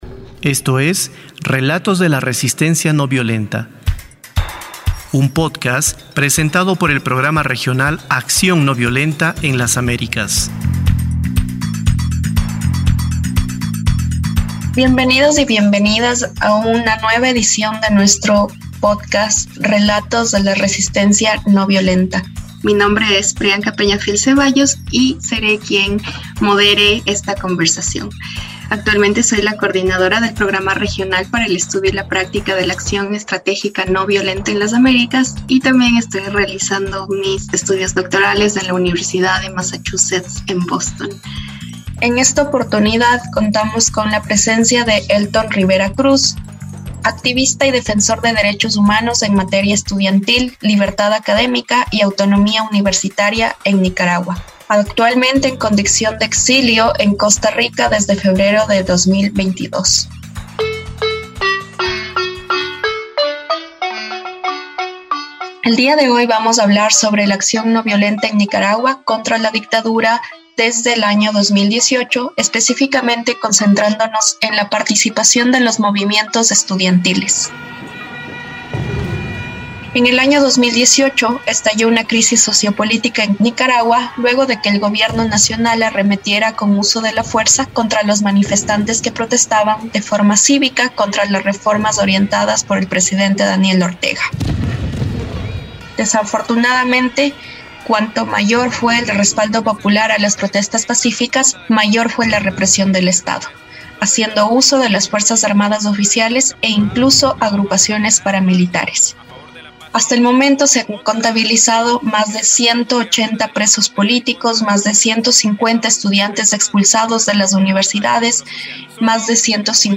Tamaño: 24.87Mb Formato: Basic Audio Descripción: Entrevista - Acción ...